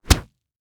Punch Body Hard